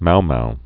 (moumou)